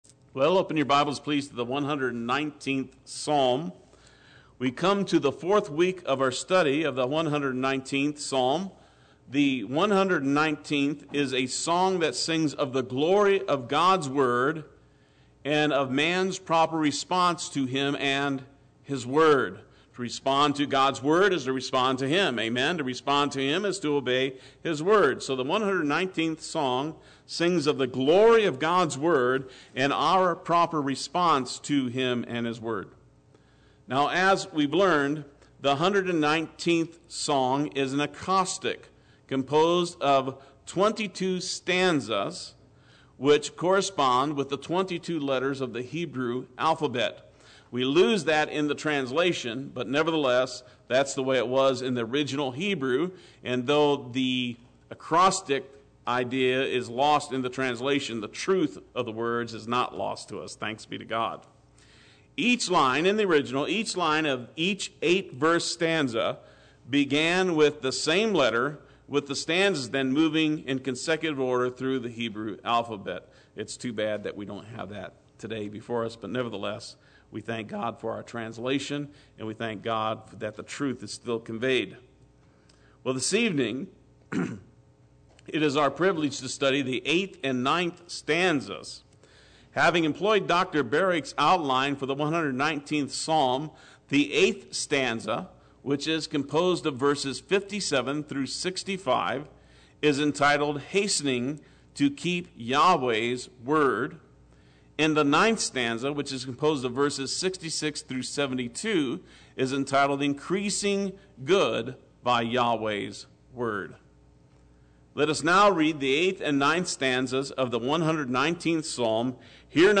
Play Sermon Get HCF Teaching Automatically.
Psalm 119:57-72 Wednesday Worship